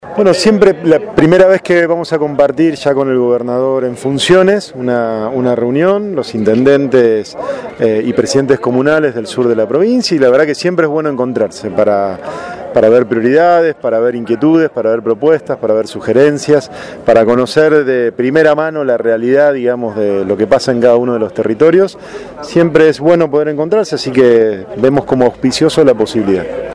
El gobernador continuó en Venado Tuerto con la ronda de encuentros con intendentes y presidentes comunales de la Región 5.